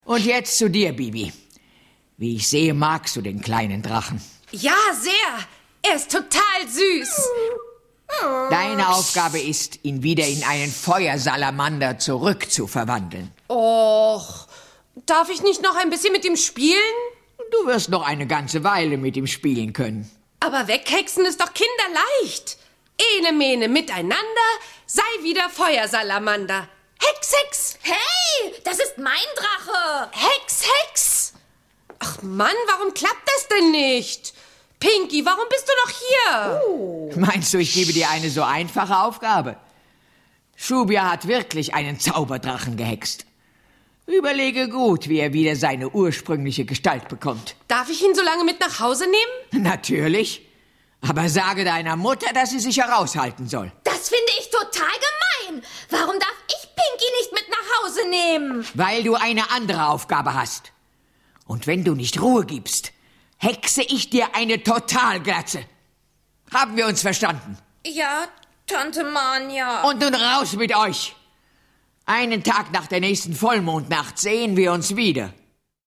Ravensburger Bibi Blocksberg - Die Hexenschule ✔ tiptoi® Hörbuch ab 4 Jahren ✔ Jetzt online herunterladen!